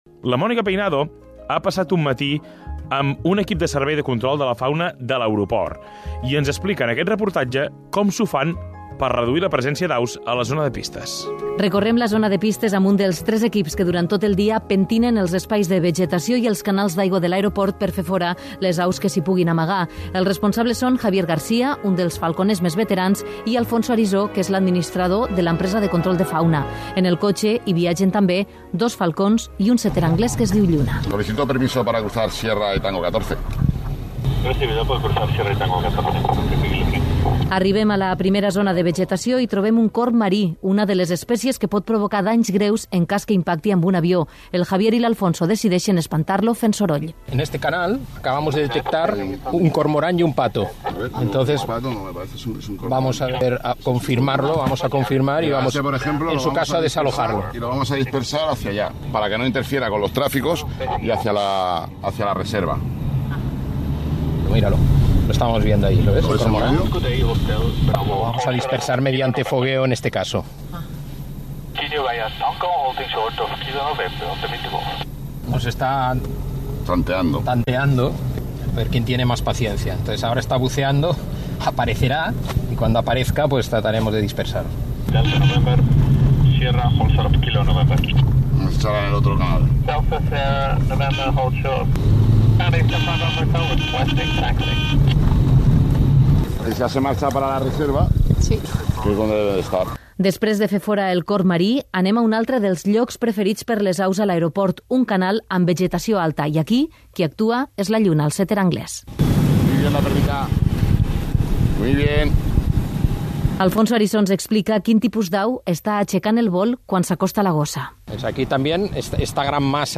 Reportatge sobre el control de la fauna a les rodalies de l'aeroport del Prat
Informatiu